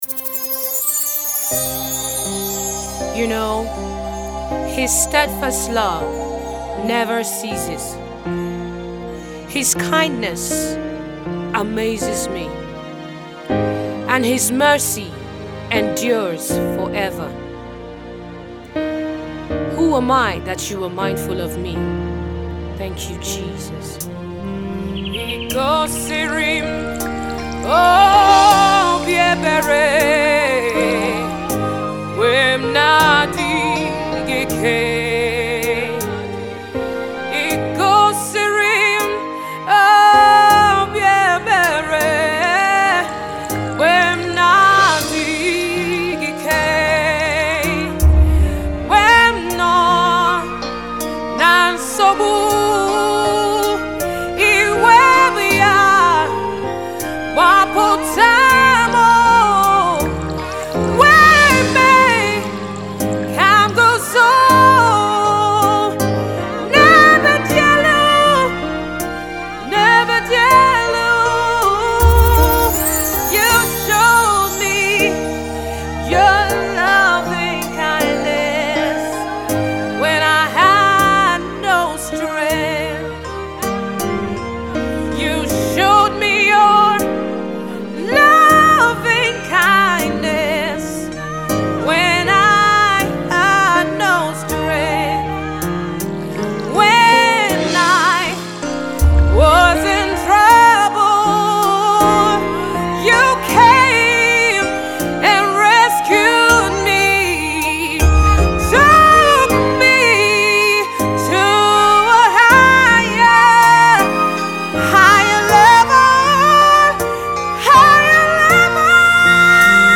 in Gospel Music